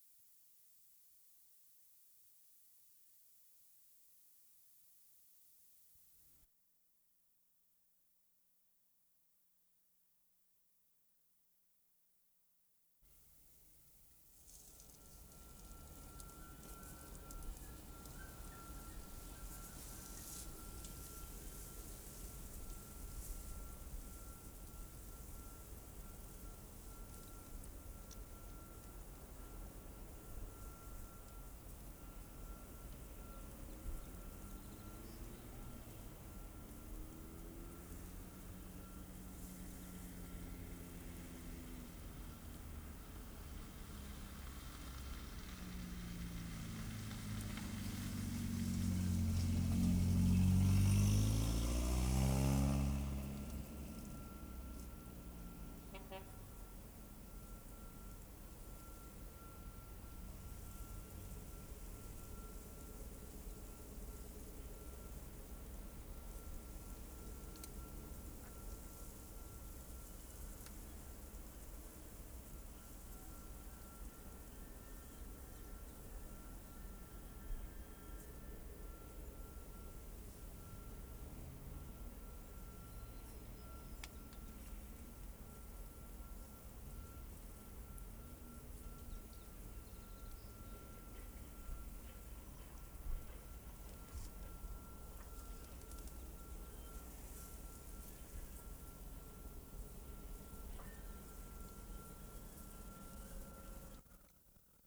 WORLD SOUNDSCAPE PROJECT TAPE LIBRARY
FRASER RIVER AMBIENCE 1'30"
10. Location near factory operation by side of the river. No water audible, only quiet industrial background. Occasional car passing.
0'30" truck horn.